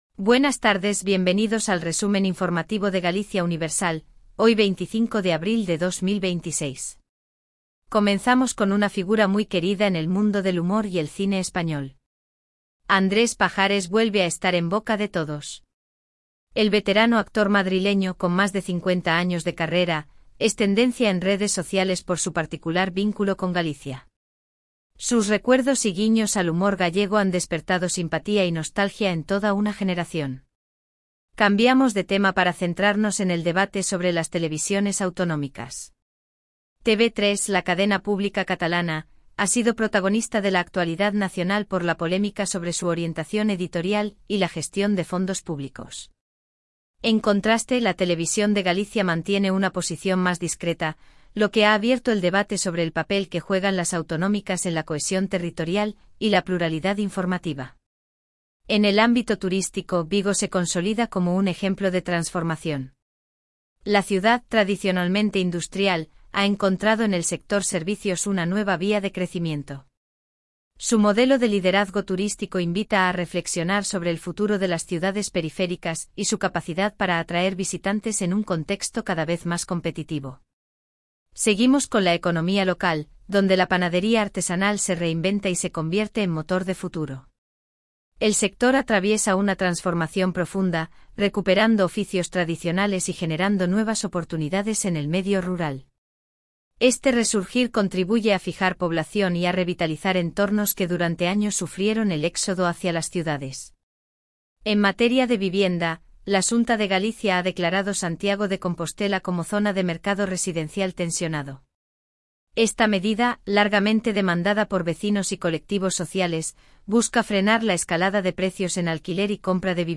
🎙 PODCAST DIARIO
Resumen informativo de Galicia Universal